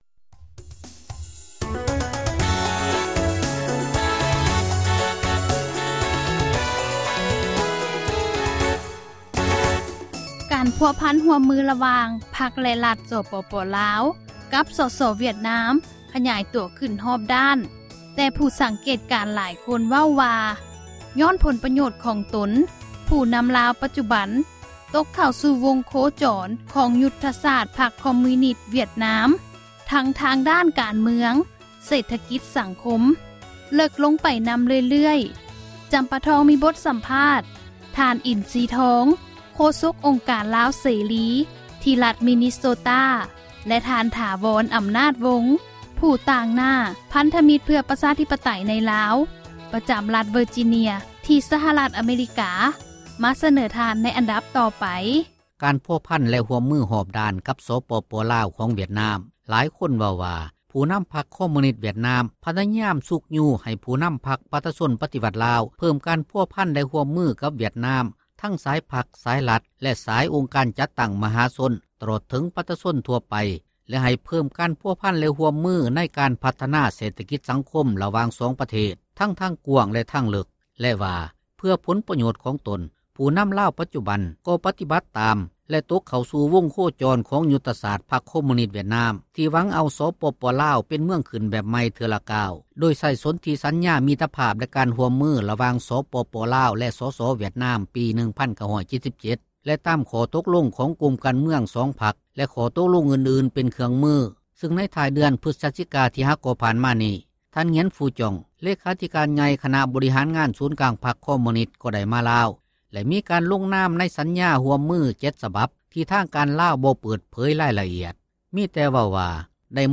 ສຳພາດນັກເຄື່ອນໄຫວຕ້ານ ຄອມມຸຍນິສ